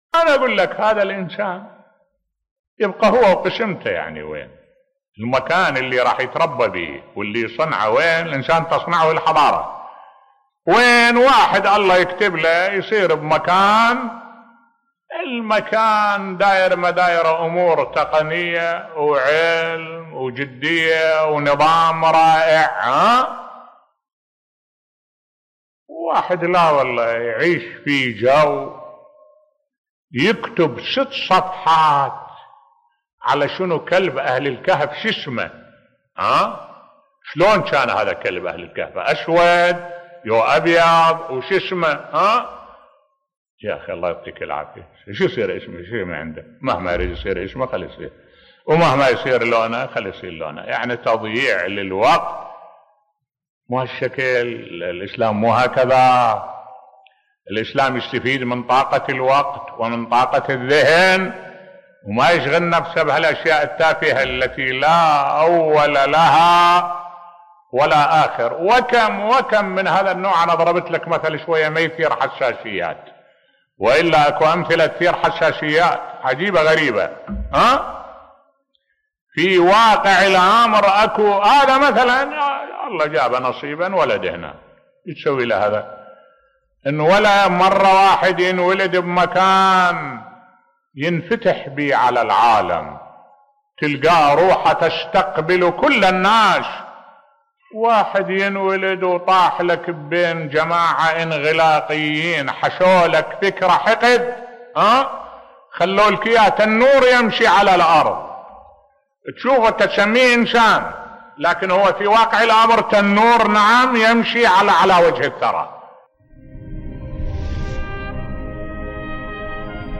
ملف صوتی تأثير الحضارة في صياغة شخصية الإنسان بصوت الشيخ الدكتور أحمد الوائلي